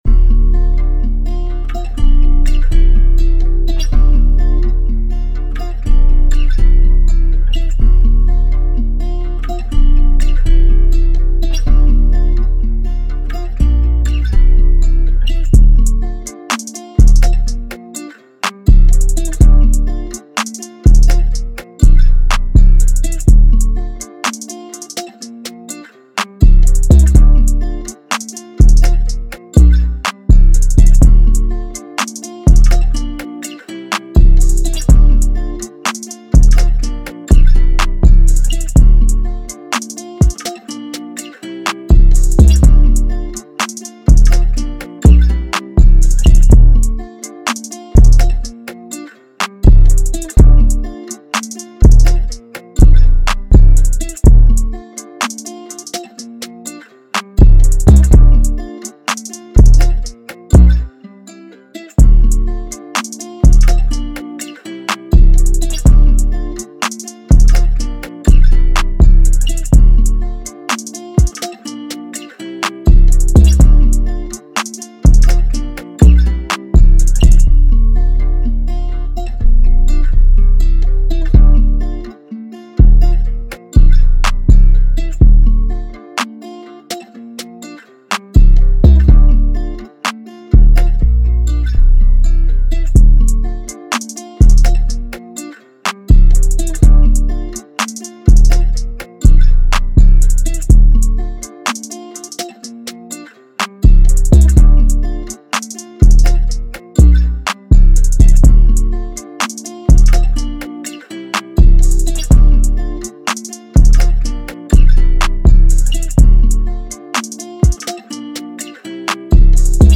official instrumental
Hip-Hop Instrumentals